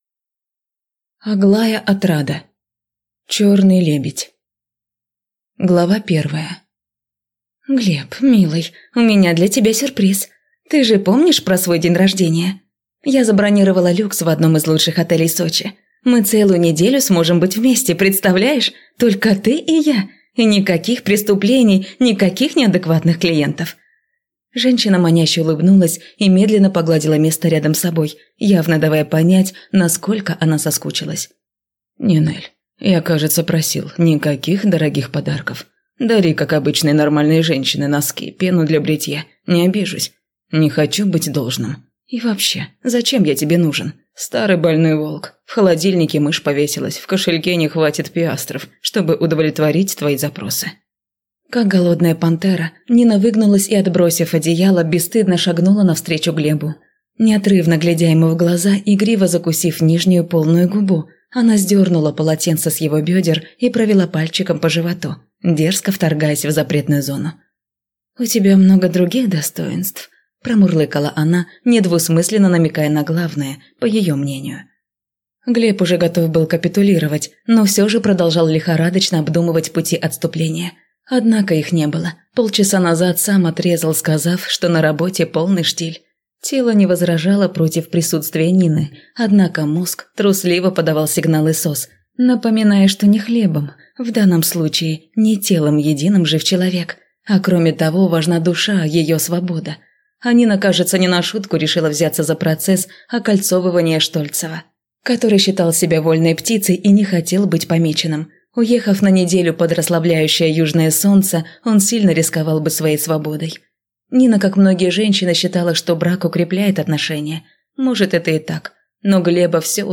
Аудиокнига Черный лебедь | Библиотека аудиокниг
Прослушать и бесплатно скачать фрагмент аудиокниги